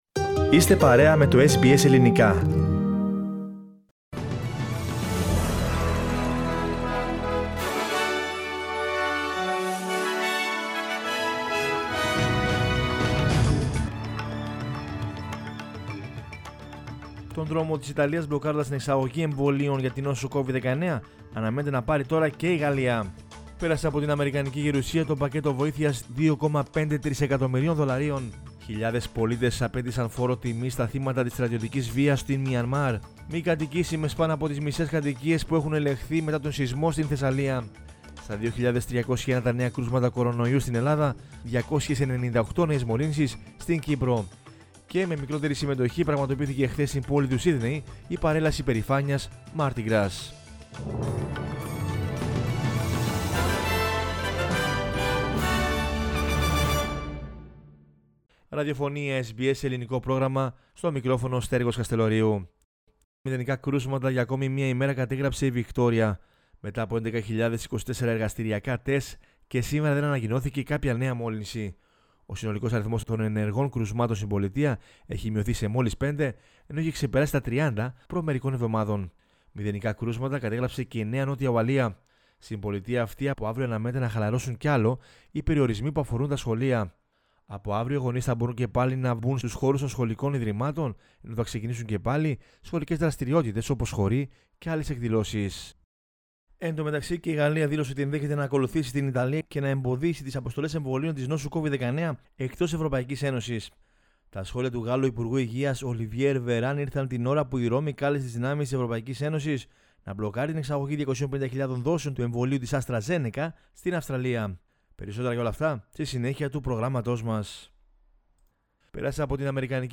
News in Greek from Australia, Greece, Cyprus and the world is the news bulletin of Sunday 7 March 2021.